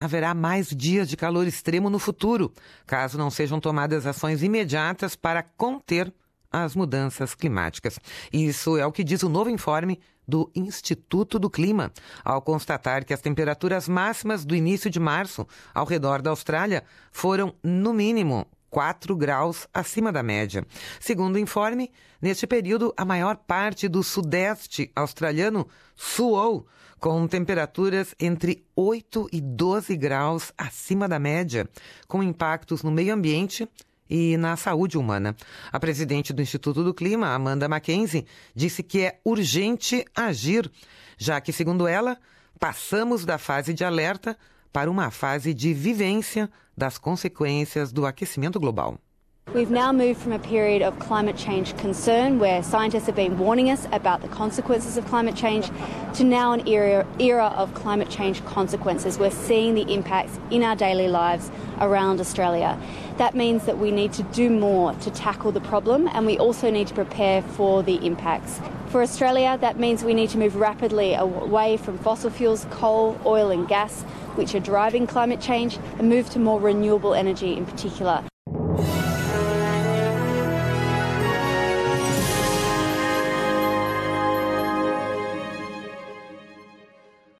A Austrália assou com as temperaturas altas recordes deste início de outono: no mínimo 4 graus mas, em algumas regiões, até 12 graus acima da média, com impactos no meio-ambiente e na saúde humana. Ouça aqui a reportagem.